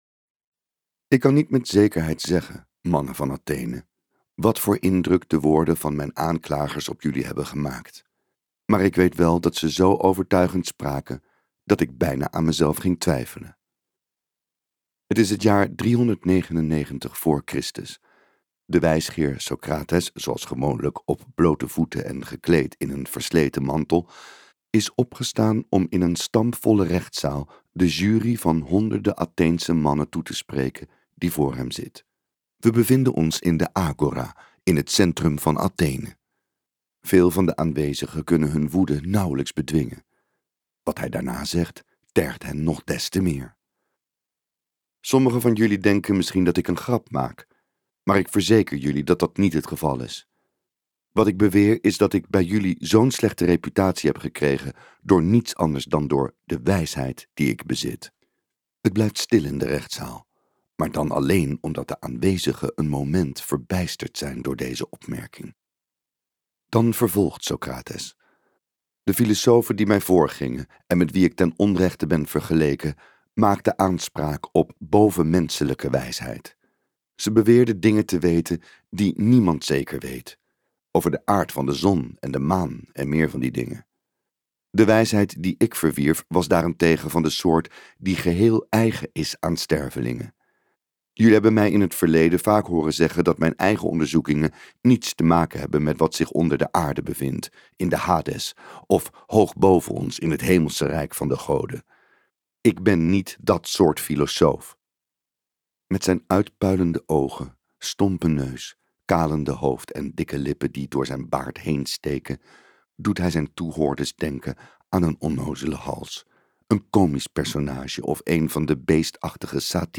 Uitgeverij Ten Have | Leer denken als socrates luisterboek